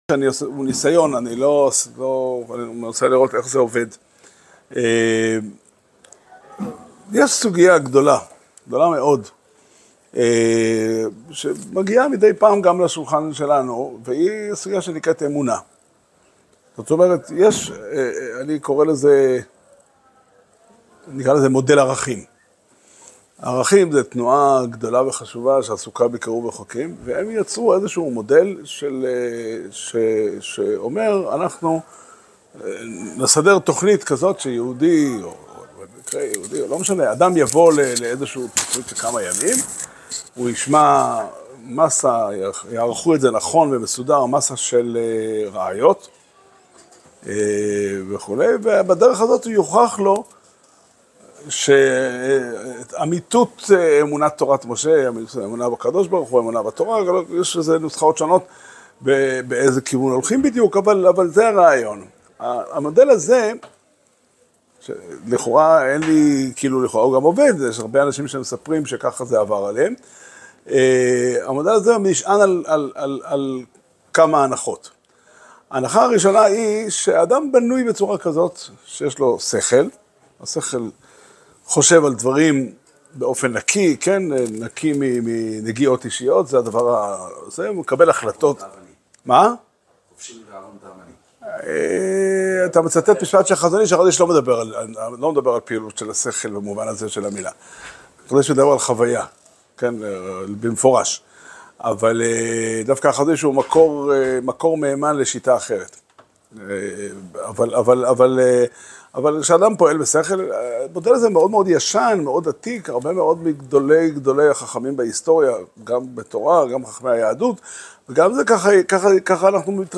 שיעור שנמסר בבית המדרש פתחי עולם בתאריך ה' תמוז תשפ"ד